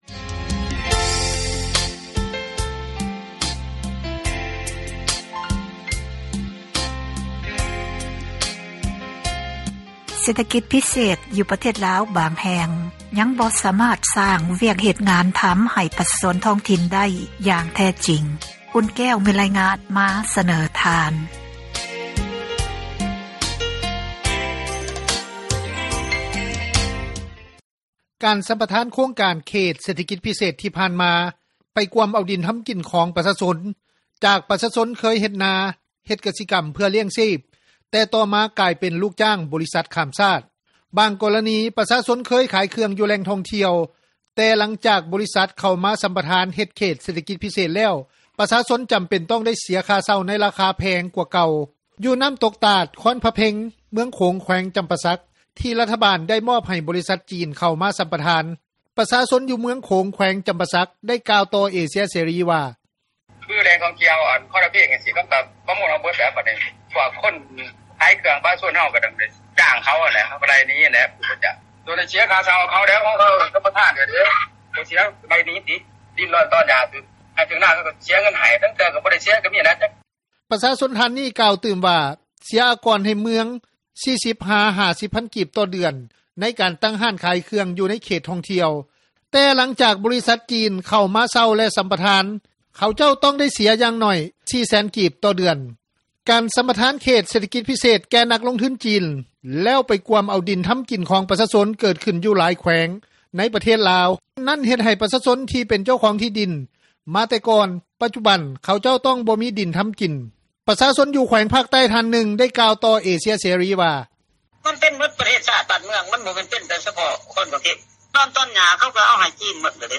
ນັກຂ່າວ ພົລເມືອງ
ປະຊາຊົນ ຢູ່ ເມືອງໂຂງ ແຂວງນຳປາສັກ ໄດ້ກ່າວຕໍ່ວິທຍຸ ເອເຊັຽ ເສຣີ ວ່າ:
ນັກກົດໝາຍ ທ່ານນຶ່ງ ຜູ້ທີ່ບໍ່ປະສົງອອກຊື່ ແລະ ສຽງ ໄດ້ກ່າວຕໍ່ວິທຍຸ ເອເຊັຽເສຣີ ວ່າ: